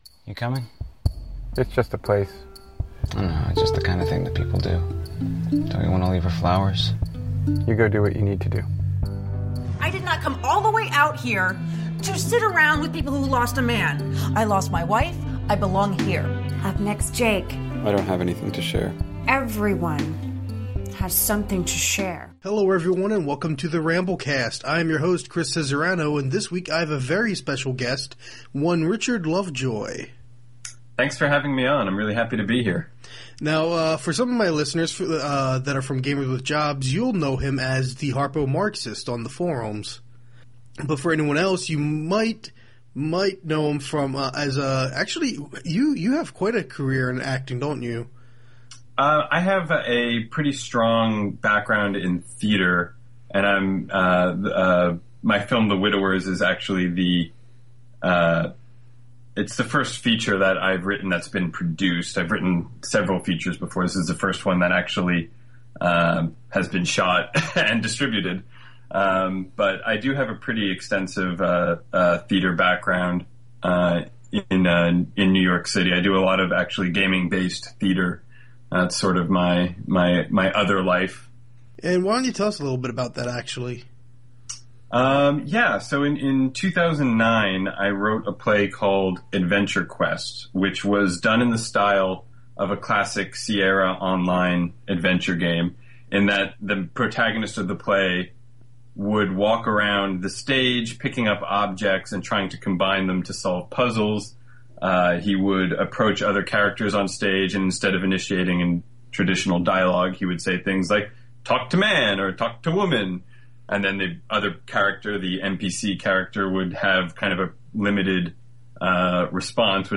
On the whole it was a really enjoyable discussion, and I certainly hope to have him back on in the future, be it for film discussion or games discussion.